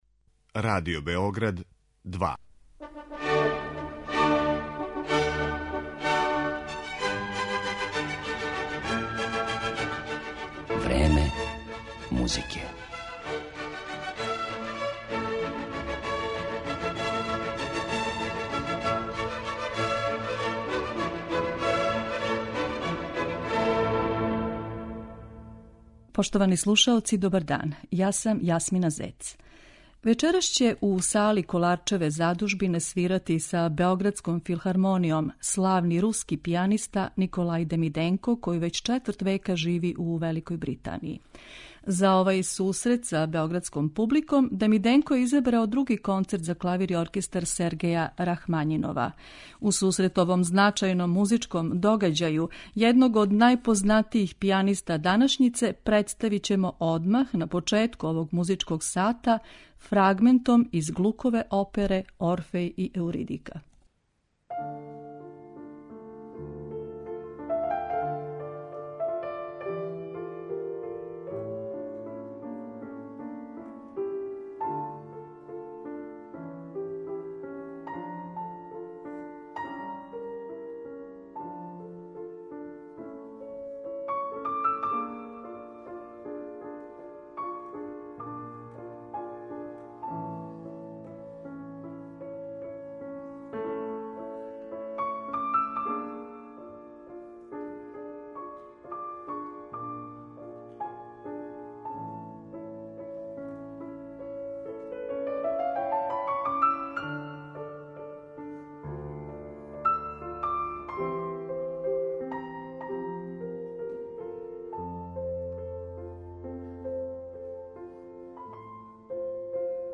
Представљамо једног од најпознатијих пијаниста данашњице
Николај Демиденко, прослављени руски пијаниста који од 1990. године живи у Лондону, свираће 29. јануара 2016. године у Коларчевој задужбини са Београдском филхармонијом.